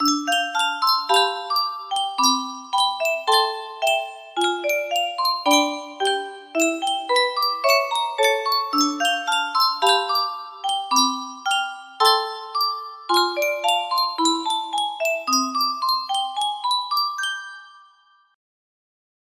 Untitled_3211 music box melody
Grand Illusions 30 (F scale)